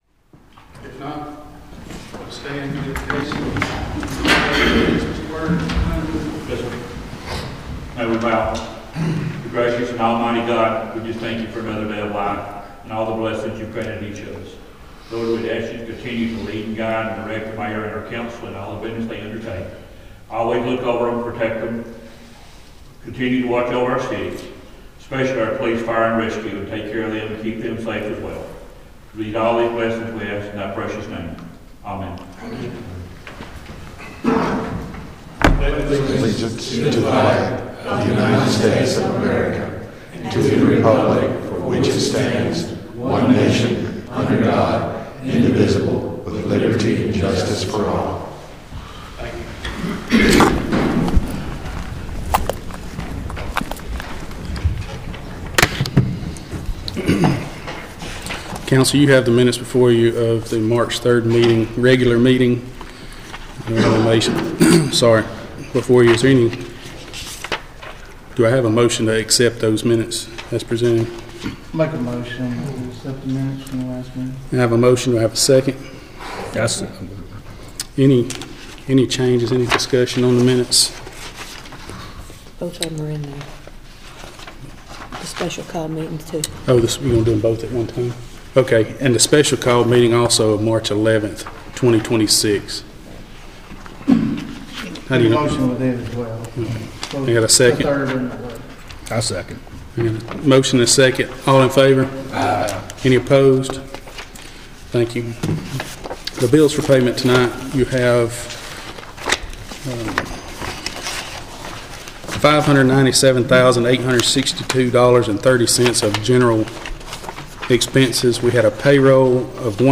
Piedmont City Council Meeting (Tue 03/18/2026) - WEIS | Local & Area News, Sports, & Weather
Piedmont, Ala.– The Piedmont City Council approved funding measures, addressed unsafe properties, and discussed ongoing concerns about budgeting practices and school traffic safety during its regular meeting Tuesday evening.